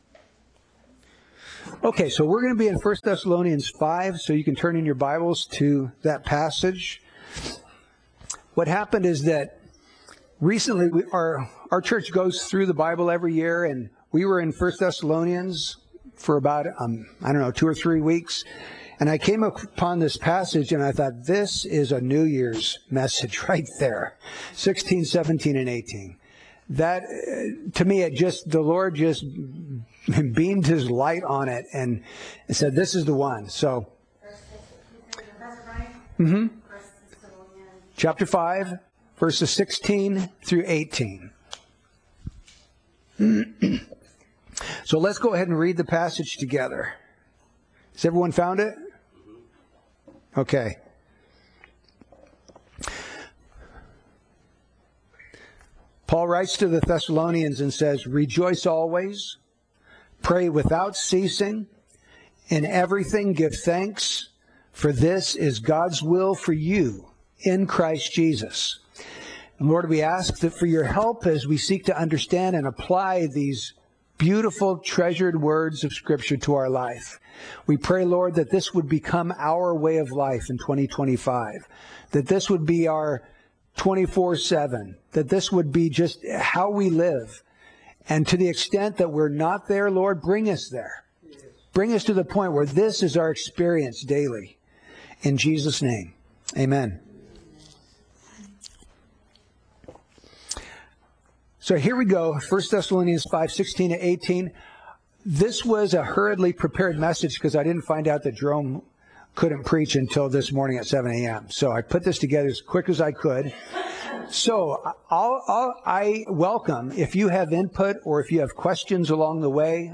Series: Holiday Messages